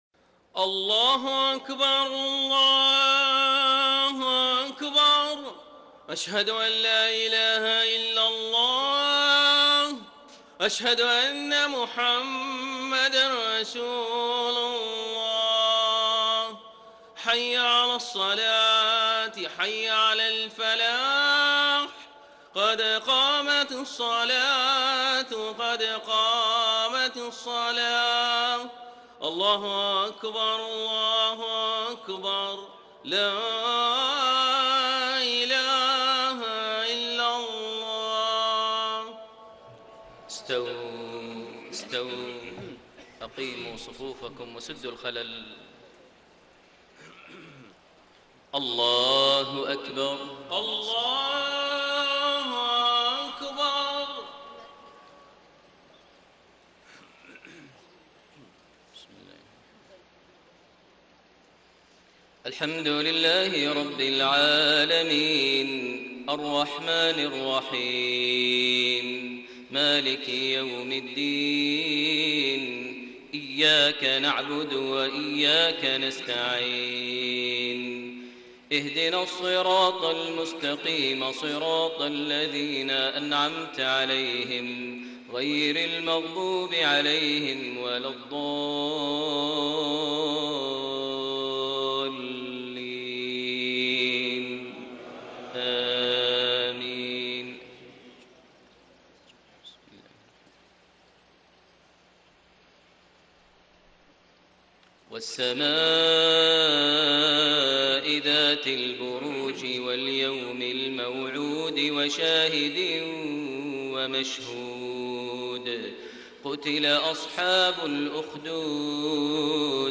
صلاة المغرب 9 شعبان 1433هـ سورة البروج > 1433 هـ > الفروض - تلاوات ماهر المعيقلي